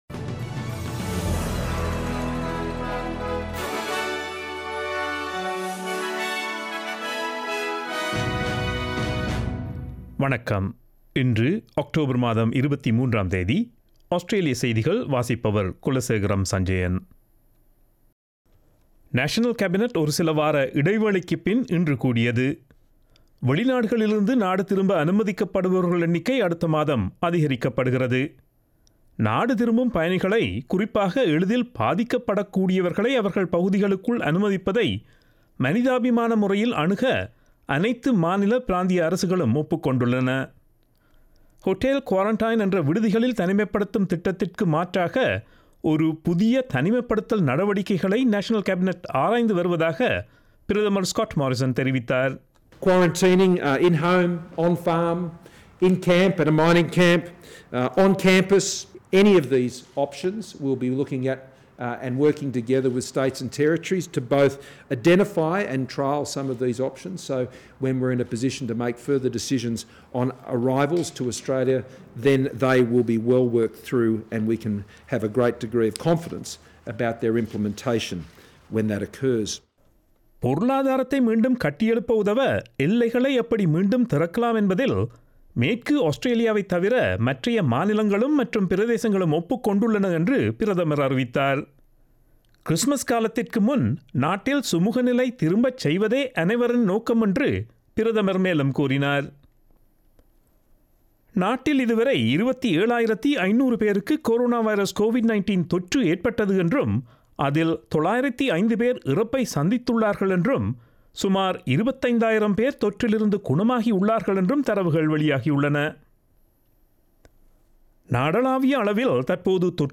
Australian news bulletin for Friday 23 October 2020.